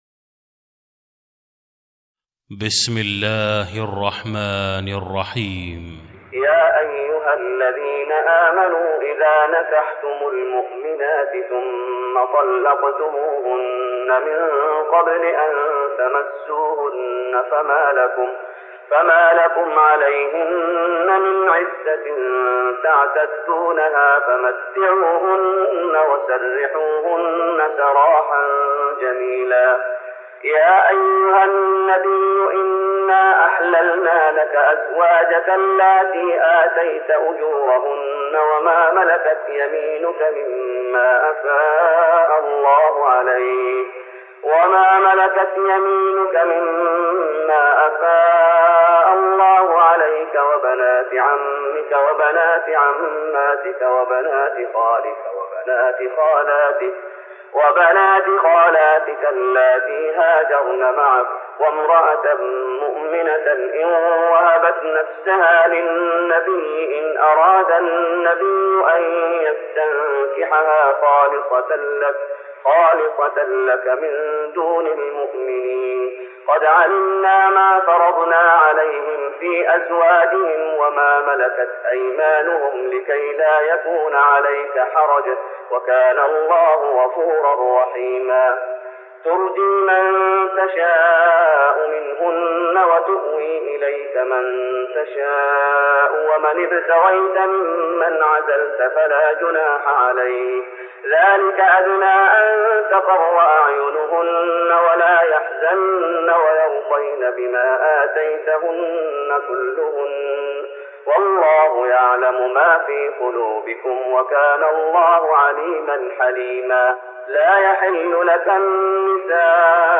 تراويح رمضان 1414هـ من سورة الأحزاب (49-58) Taraweeh Ramadan 1414H from Surah Al-Ahzaab > تراويح الشيخ محمد أيوب بالنبوي 1414 🕌 > التراويح - تلاوات الحرمين